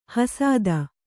♪ hasāda